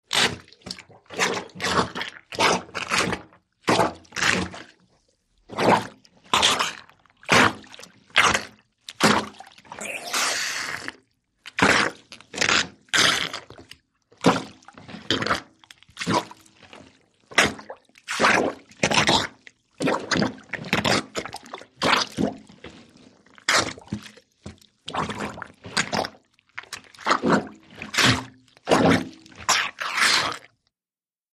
RhythSuctionSlwChu PE691301
HOUSEHOLD SUCTION: INT: Rhythmic suction, slow, churning plumbers helper on laundry.